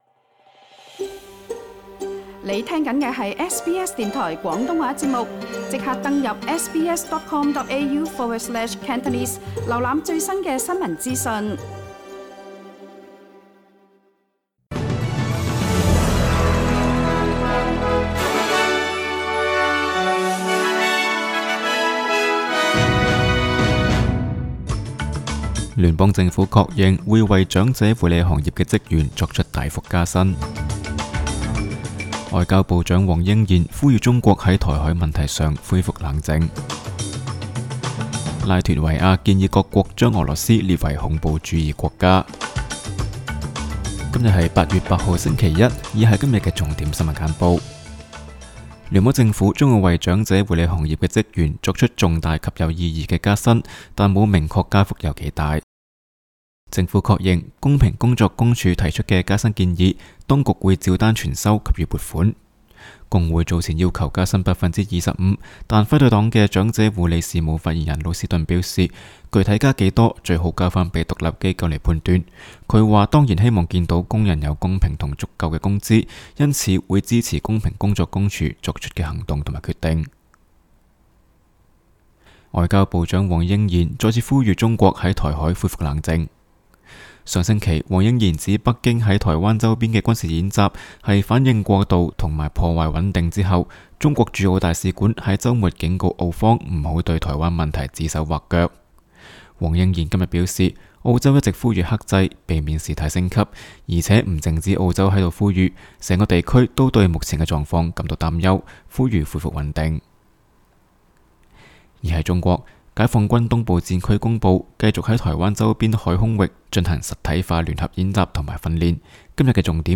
SBS 新聞簡報（8月8日） 06:25 SBS 廣東話節目新聞簡報 SBS廣東話節目 View Podcast Series Follow and Subscribe Apple Podcasts YouTube Spotify Download (7.82MB) Download the SBS Audio app Available on iOS and Android 請收聽本台為大家準備的每日重點新聞簡報。